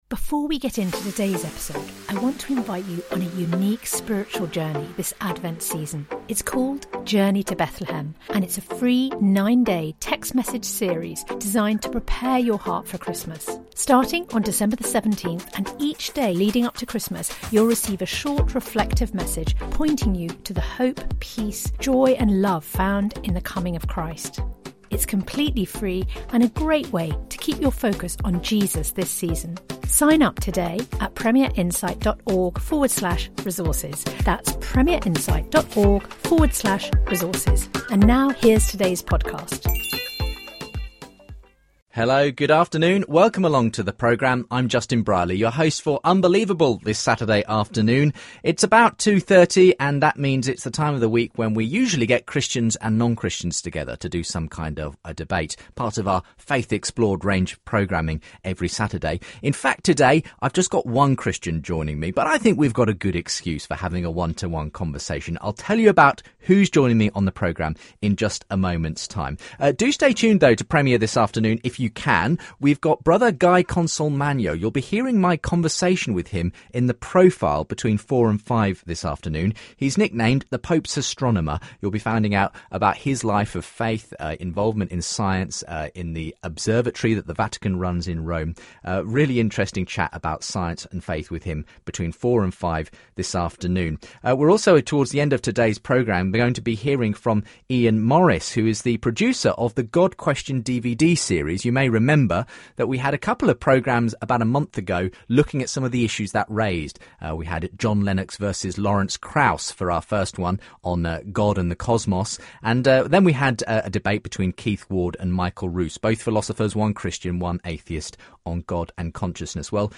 He also answers a number of listener questions that have come in on Facebook and Twitter He answers questions on hell, Satan, creation, Adam and Eve, Old Testament texts, the role of women and more.